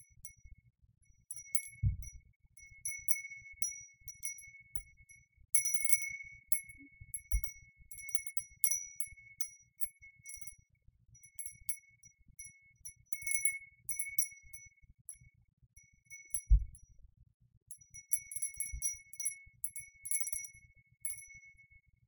Gentle wind chimer
chime ding effect freesound gentle sound wind sound effect free sound royalty free Sound Effects